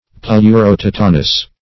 Search Result for " pleurothotonus" : The Collaborative International Dictionary of English v.0.48: Pleurothotonus \Pleu`ro*thot"o*nus\, n. [NL., fr. Gr. pleyro`qen from the side + to`nos a stretching.]